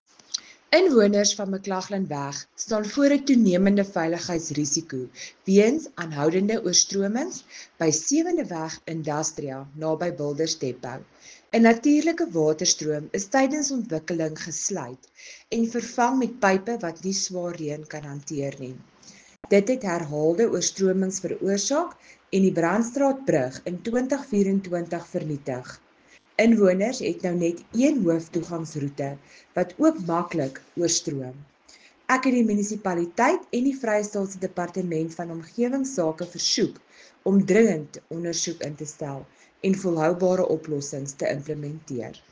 Afrikaans soundbites by Cllr Marelize Boeije and